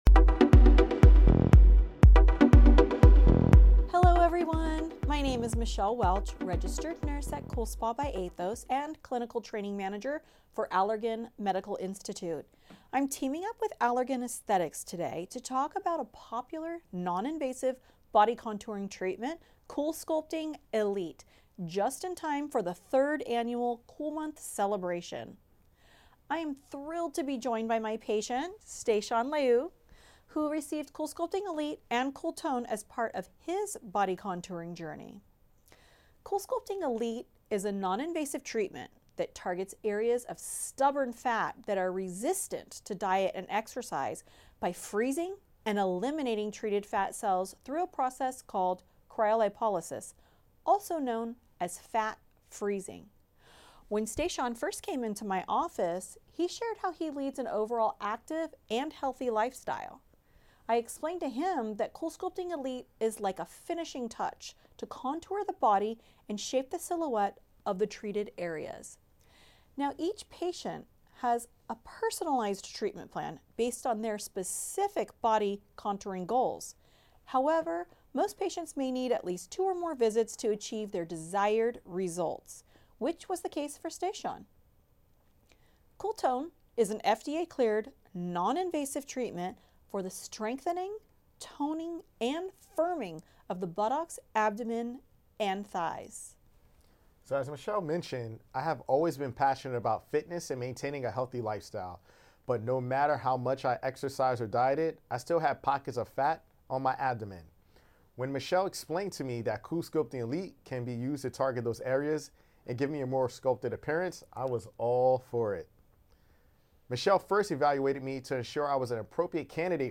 at the LifeMinute Studios